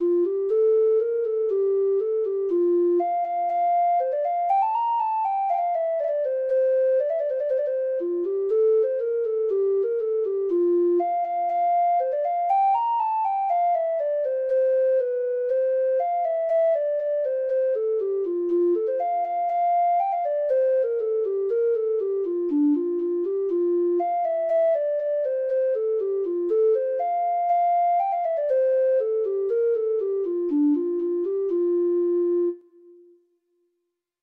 Traditional Music of unknown author.
Treble Clef Instrument Sheet Music